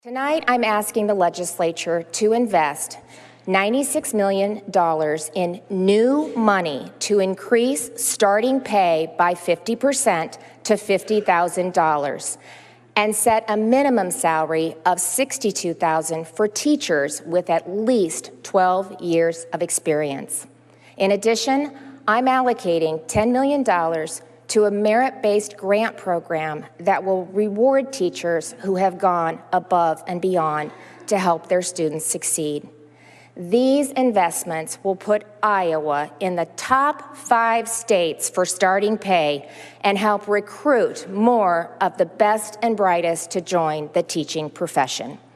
Gov. Kim Reynolds discussed more proposed reforms she would like to implement for Iowa education during the Condition of the State address on Tuesday evening.